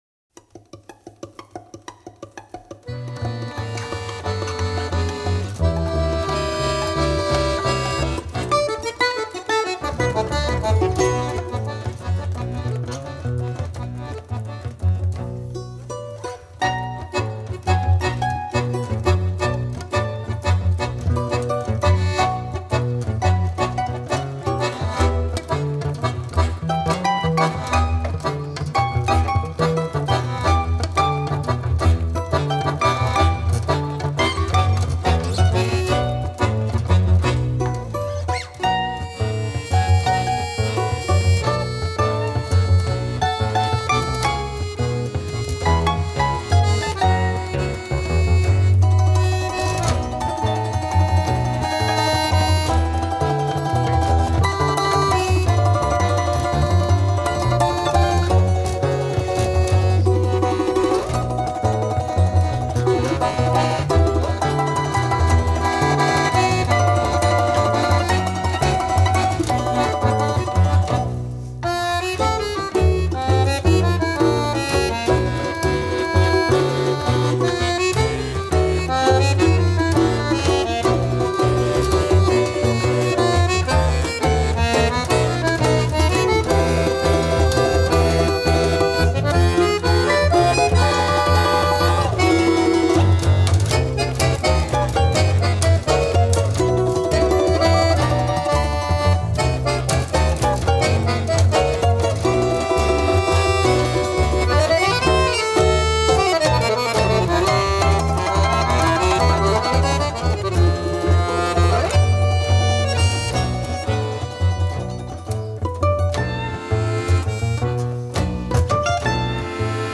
Balalaika
Kontrabass